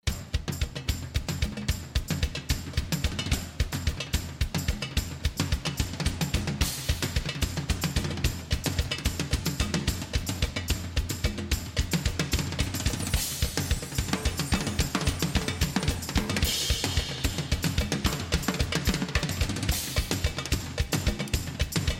Jaka to piosenka - Rock / Metal